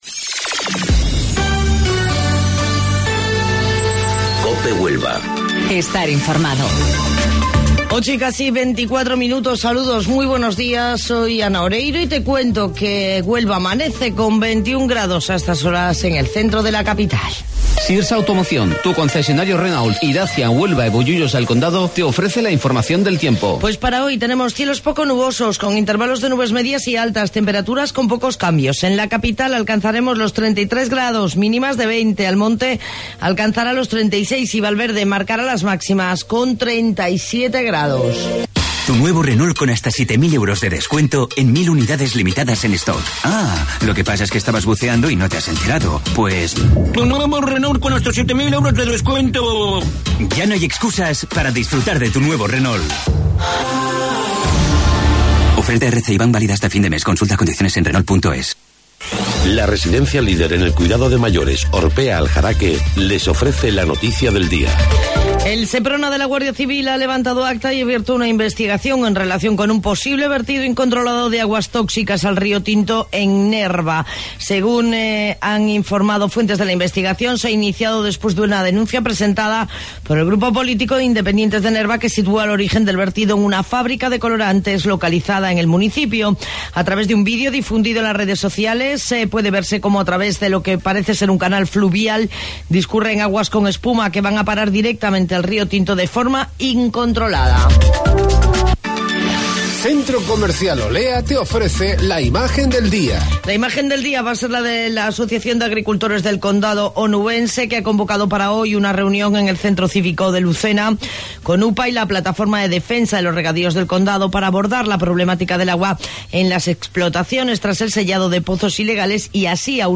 AUDIO: Informativo Local 08:25 del 7 de Agosto